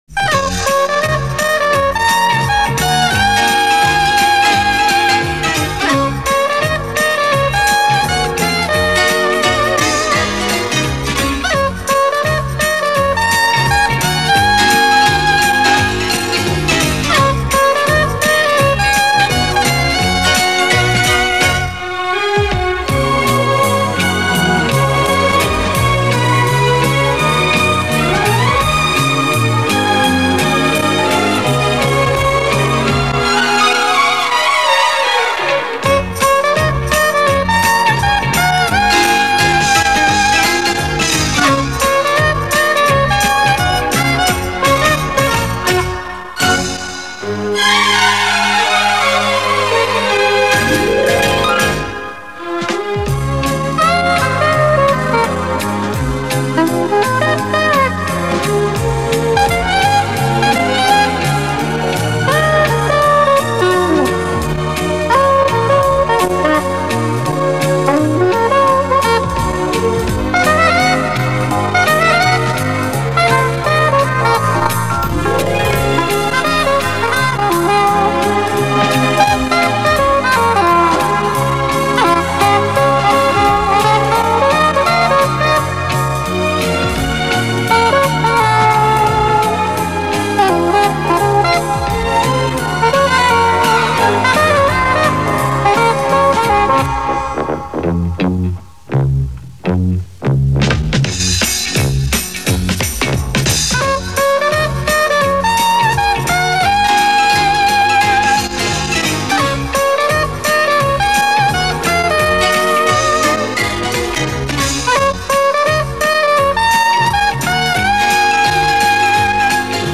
запись с эфира